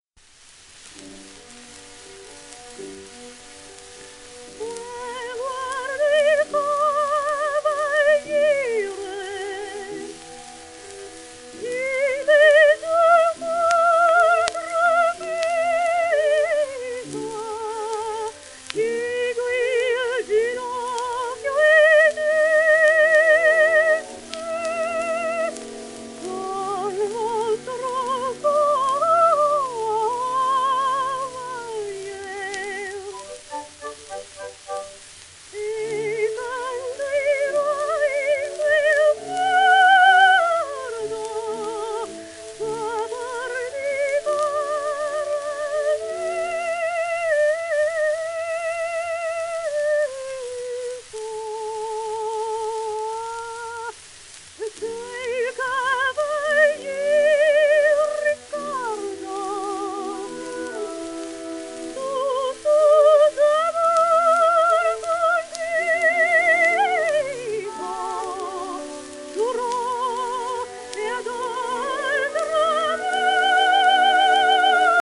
w/オーケストラ
旧 旧吹込みの略、電気録音以前の機械式録音盤（ラッパ吹込み）